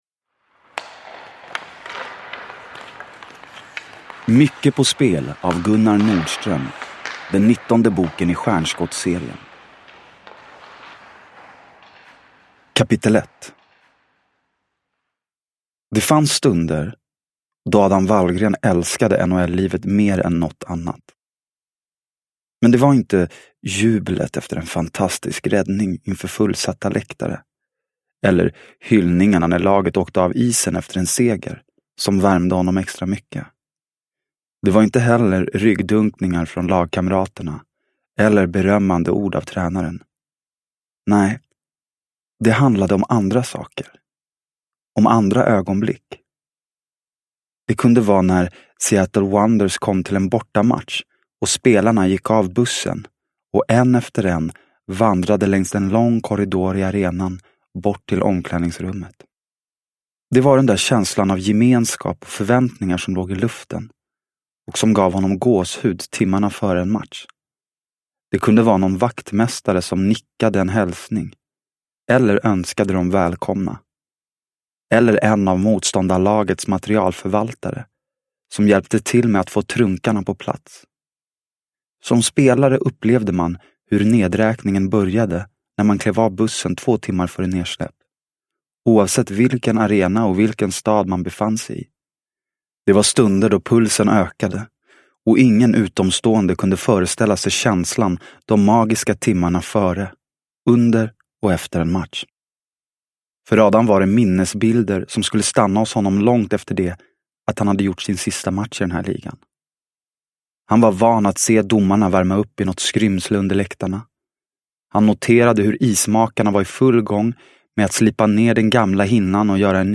Mycket på spel – Ljudbok – Laddas ner